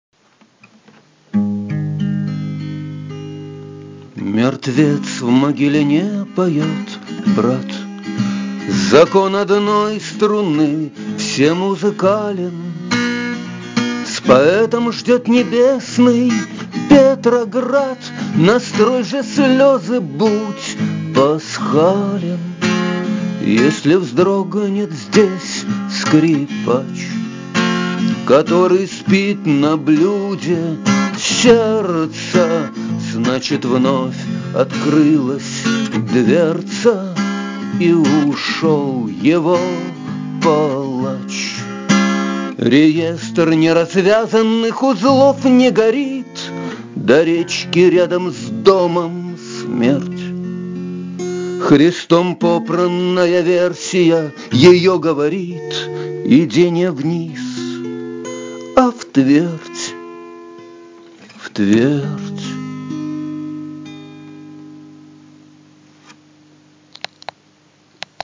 • Жанр: Бардрок